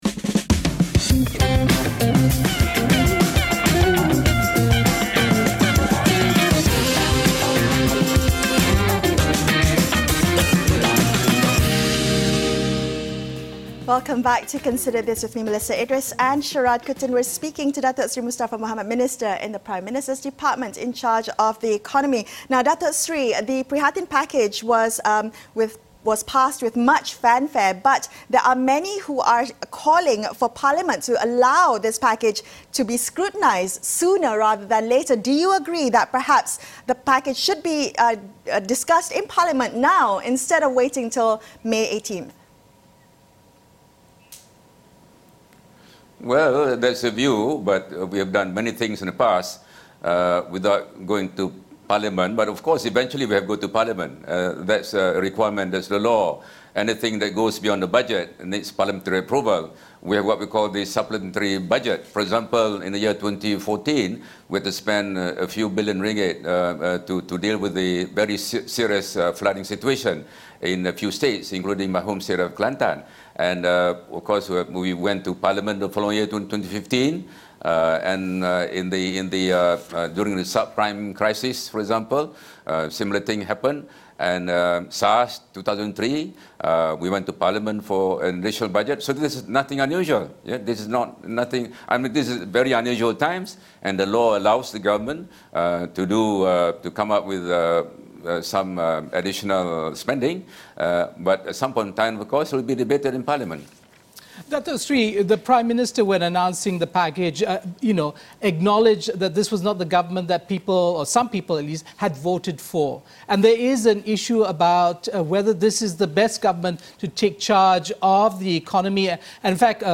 speak to Datuk Seri Mustapa Mohamed, Minister in the Prime Minister’s Department, in charge of the economy.